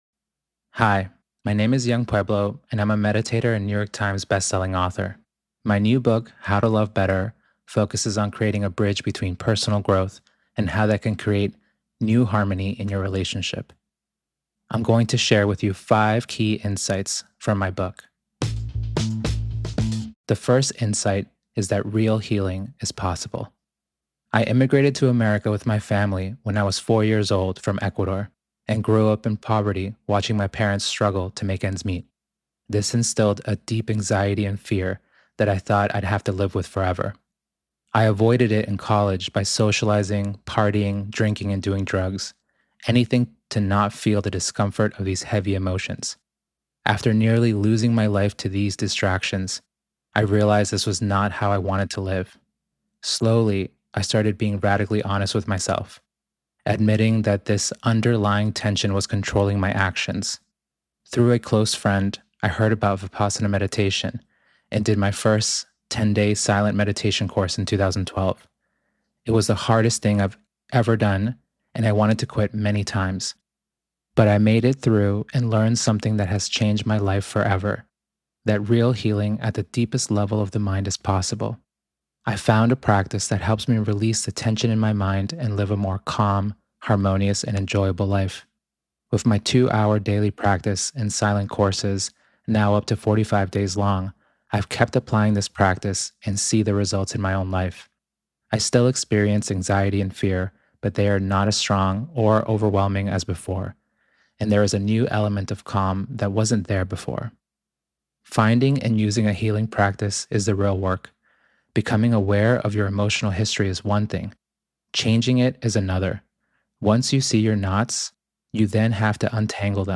Listen to the audio version—read by Yung himself—in the Next Big Idea App.